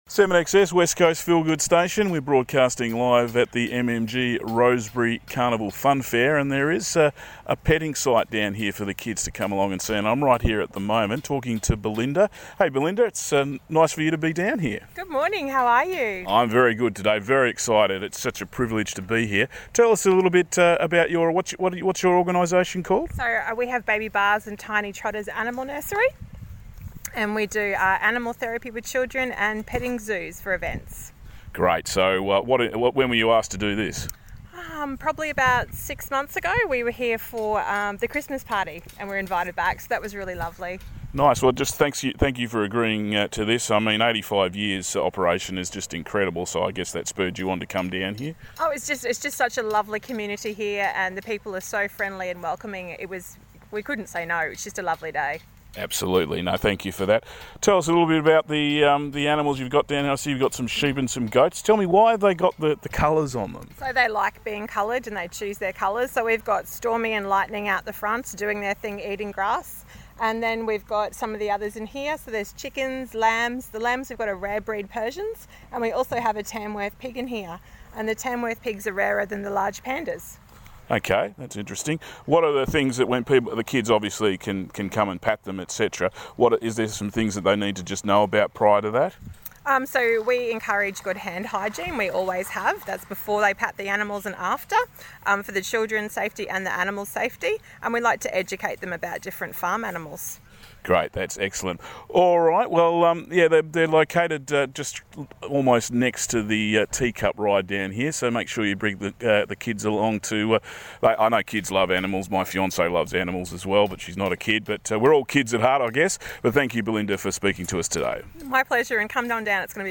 baby Baas and Tiny Toddlers petting zoo at Saturdays OB in Rosebery for the Carnival Fun Fair.